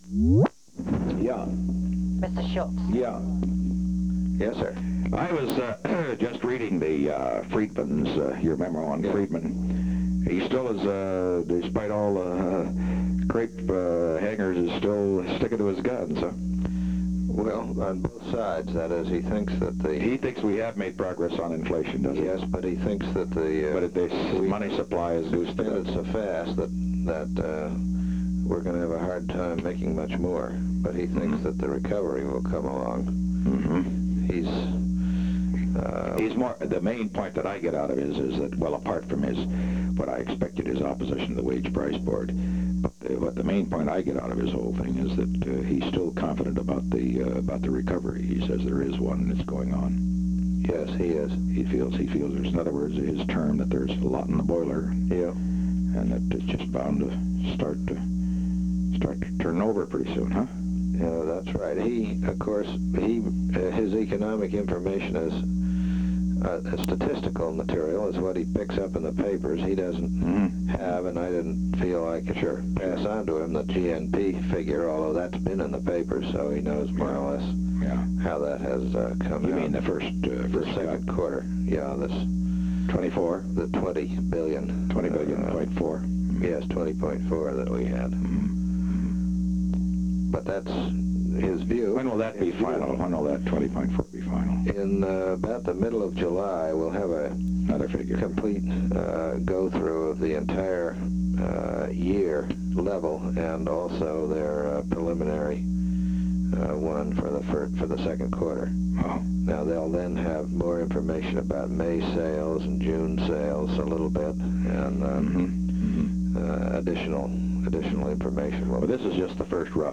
Secret White House Tapes
Location: White House Telephone
The President talked with George P. Shultz.